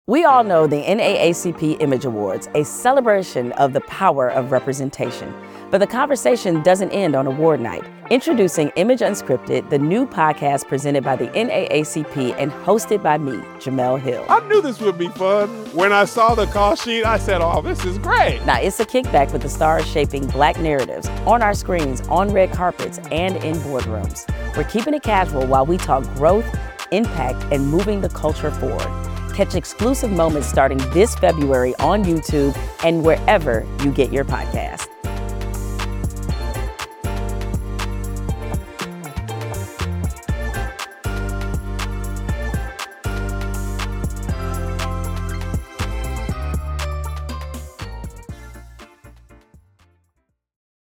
Hosted by Jemele Hill. This NAACP+ project is highlighting the actual moves that shape careers, creativity, and keep the culture moving forward.